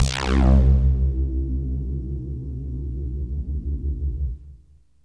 SYNTH LEADS-1 0010.wav